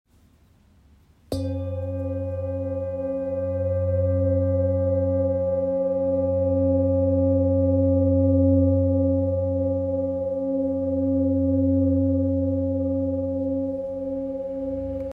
This enhanced oscillation makes it perfect for bodywork, as its deep, resonant tones penetrate the body, promoting relaxation, balancing energy centers, and restoring harmony on a cellular level.
Its rich tones and sustained vibrations make it a versatile tool for meditation, energy healing, and therapeutic use.